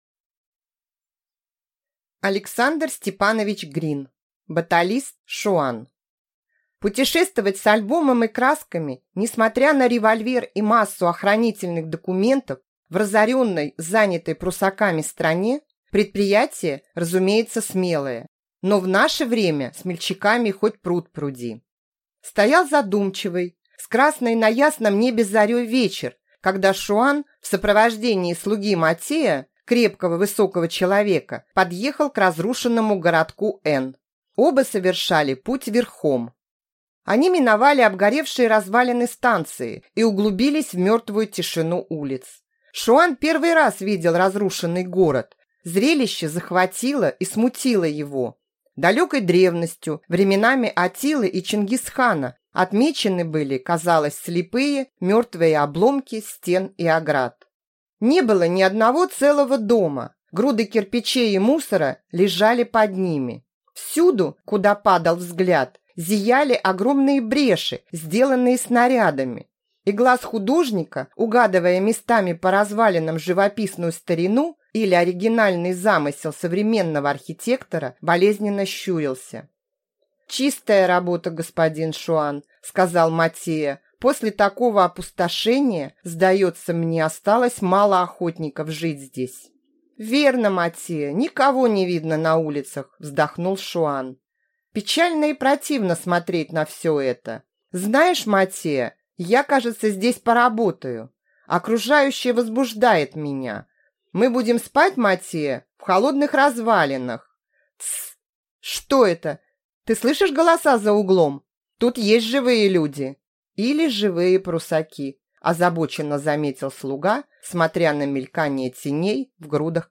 Аудиокнига Баталист Шуан | Библиотека аудиокниг